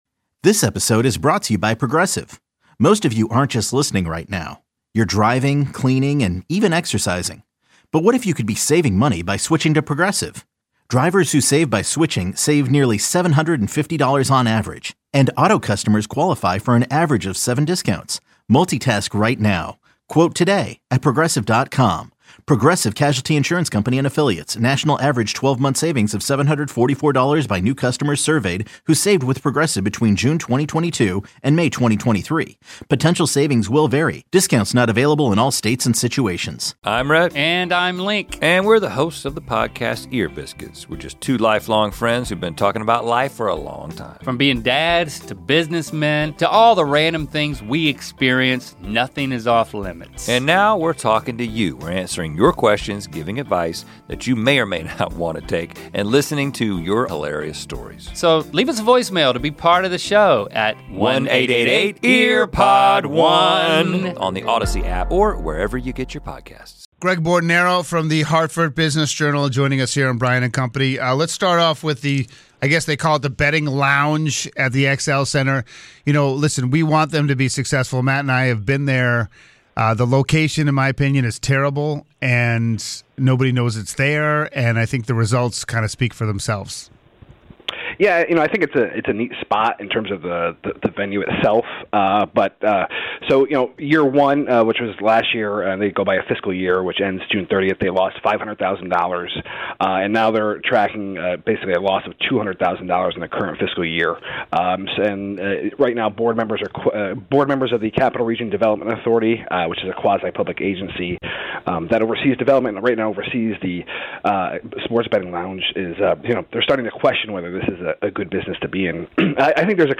News, weather, traffic and sports plus, interviews with the people making the news each day.